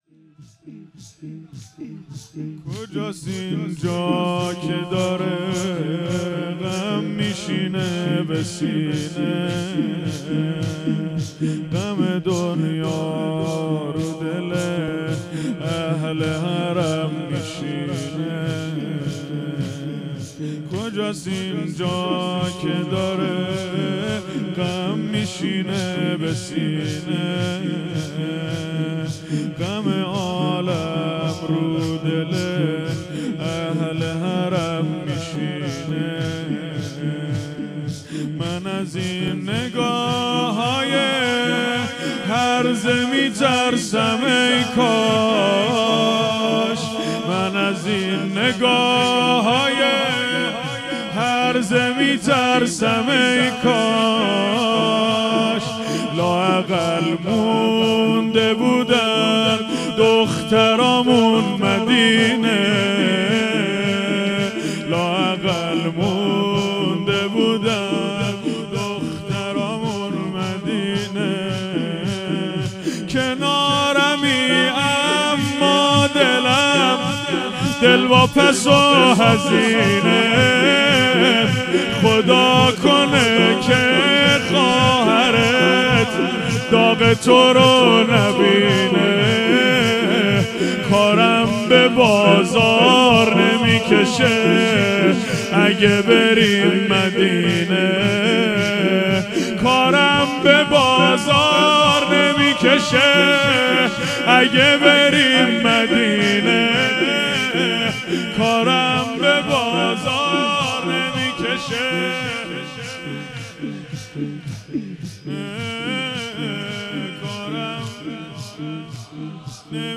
هیئت حسن جان(ع) اهواز - زمینه
دهه اول محرم الحرام ۱۴۴۴